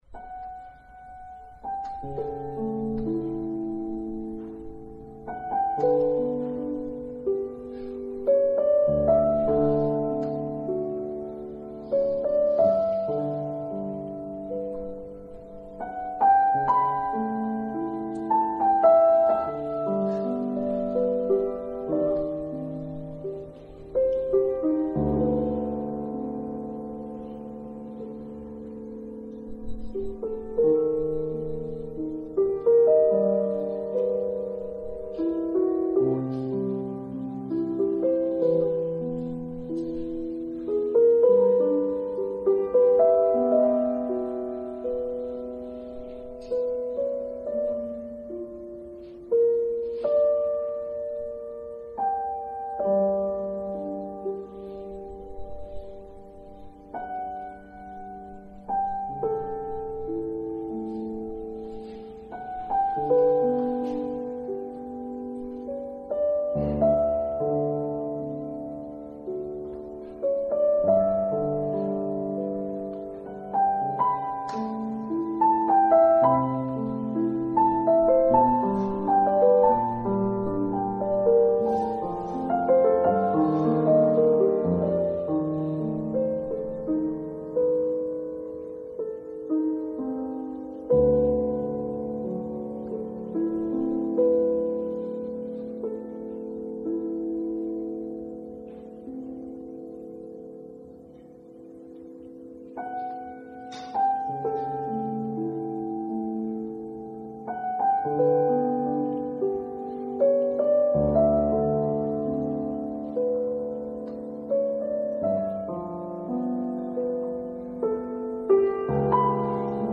піаніст
(Записано  в  залі,  із  слухачами,  тому  чутно  гомін).